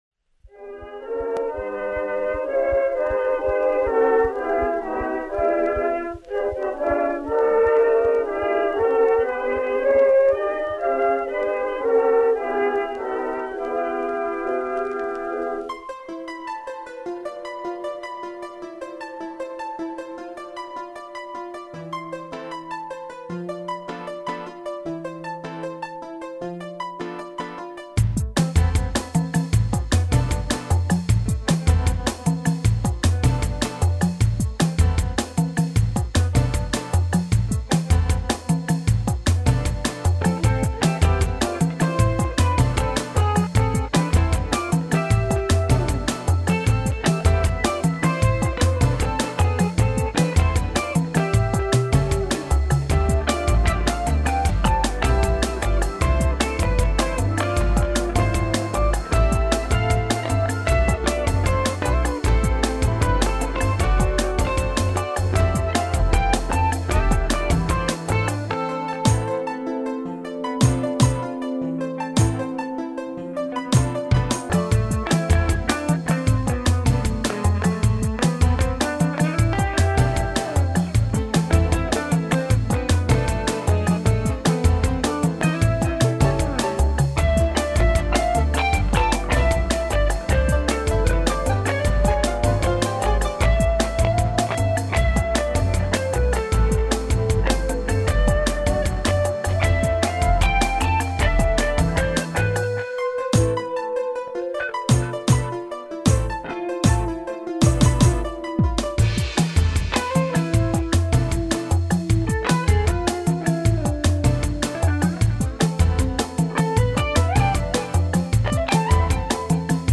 A simple guitar rendition of a Christmas Classic